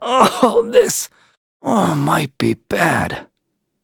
Kibera-Vox_Dead_b.wav